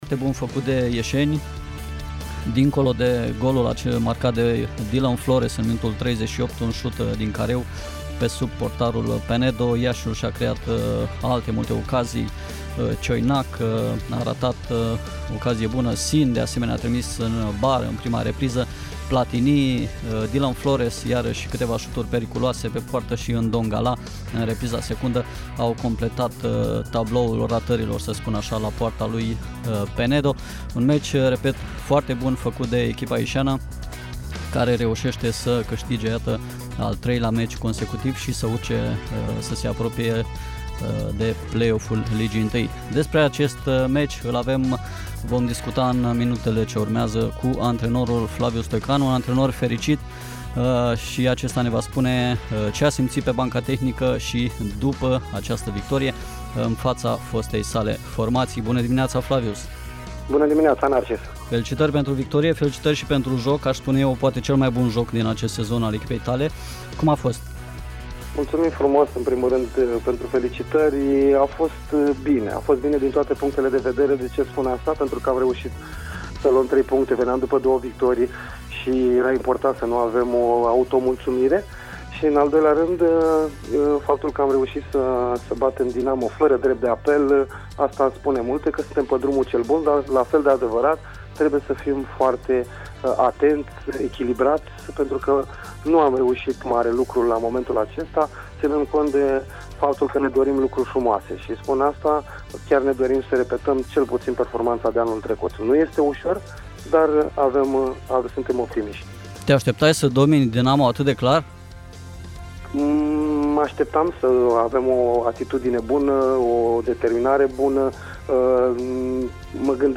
Antrenorul Flavius Stoican, în direct la Radio HIT: ”Am bătut pe Dinamo fără drept de apel”
Antrenorul Politehnicii Iași, Flavius Stoican, a vorbit în direct la Radio HIT, luni dimineață, despre meciul cu Dinamo, câștigat de echipa din Copou cu scorul de 1-0. Tehnicianul s-a declarat de evoluția elevilor săi, care au făcut unul dintre cele mai bune meciuri din acest debut de sezon, dar a îndemnat la calm.